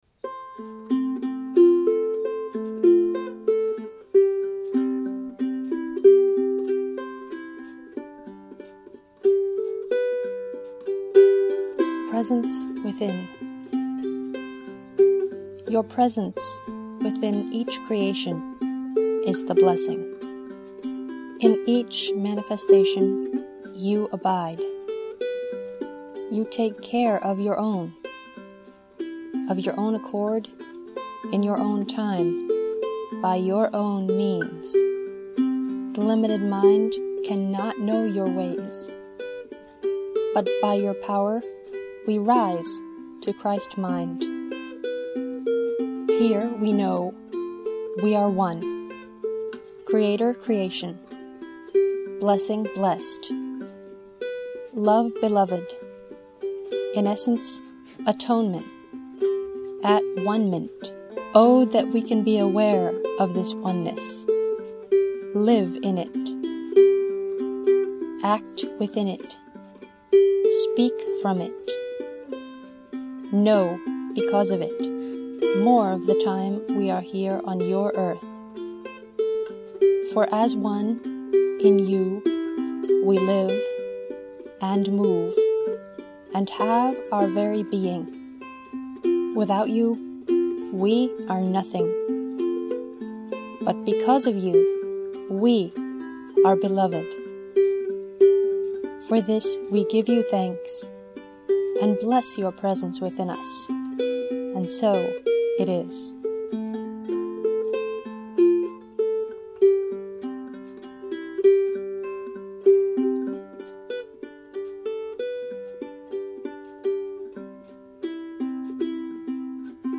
Instrument: Walnut Concert Flea Ukulele